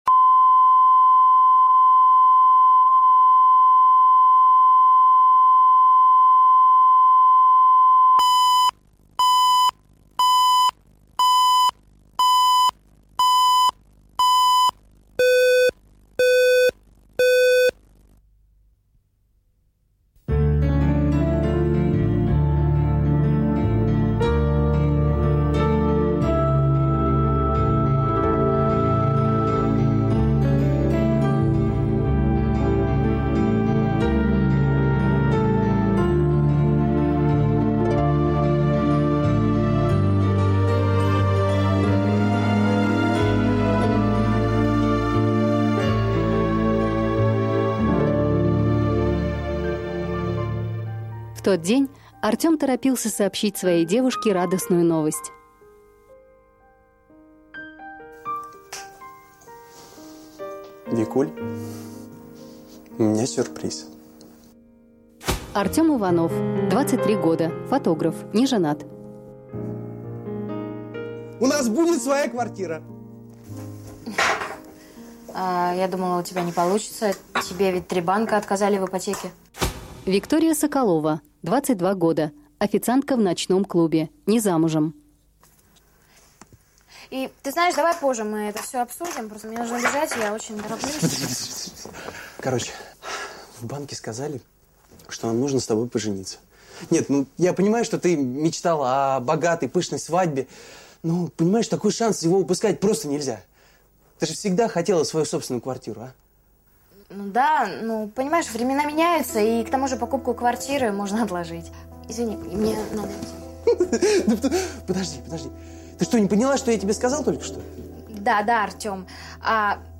Аудиокнига Пятый размер | Библиотека аудиокниг
Прослушать и бесплатно скачать фрагмент аудиокниги